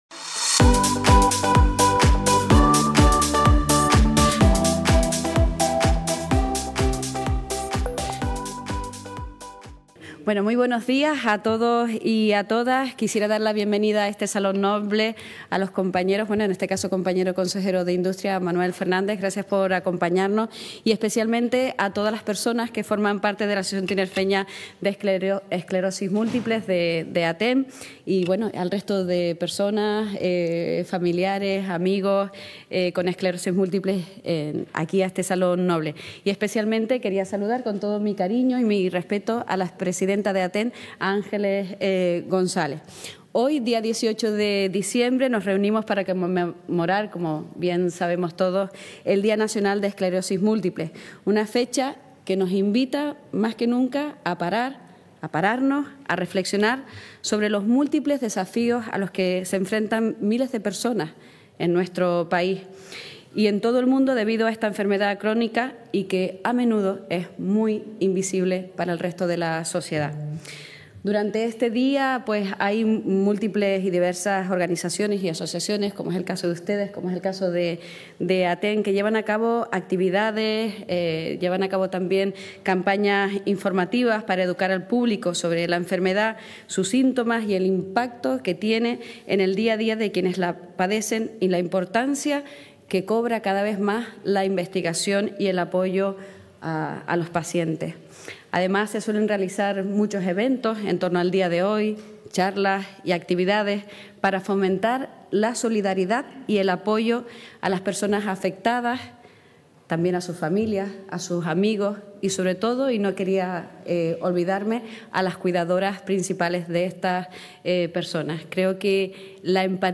El Salón Noble del Cabildo de Tenerife acogió esta mañana (miércoles 18) la lectura de un manifiesto para conmemorar el Día Nacional de la Esclerosis Múltiple.